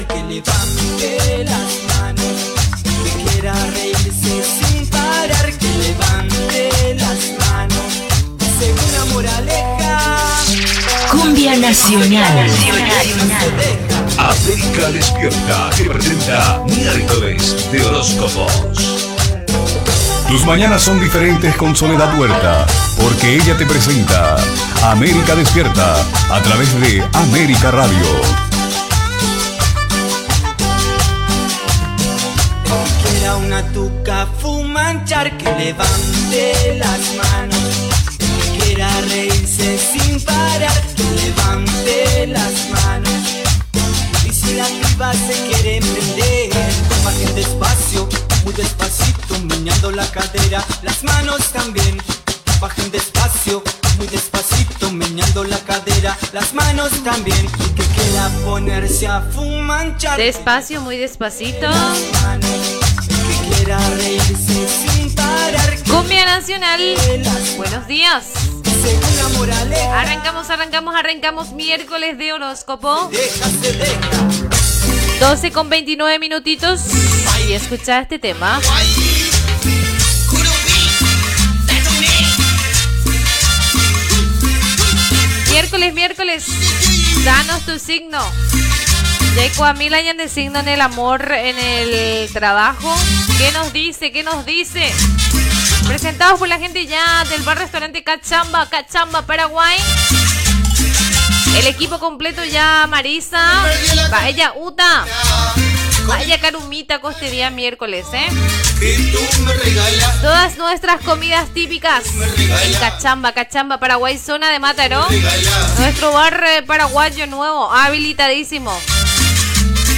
Identificació del programa, presentació de "Míércoles de hosóscopo", hora, publicitat, indicatiu, horòscop
Entreteniment